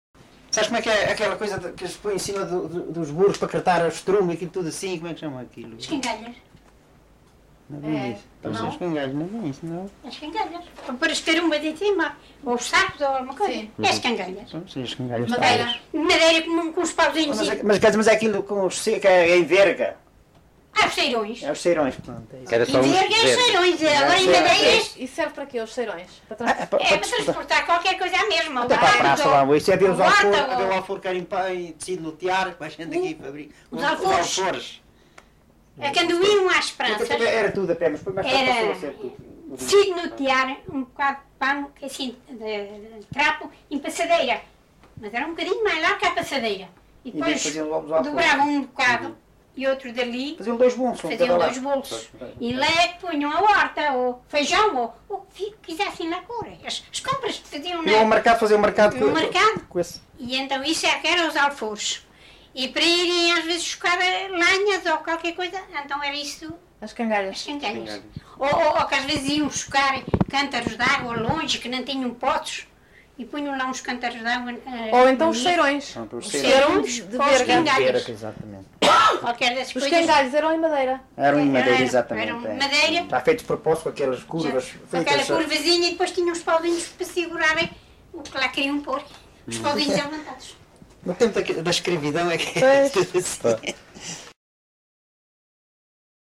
LocalidadeMoita do Martinho (Batalha, Leiria)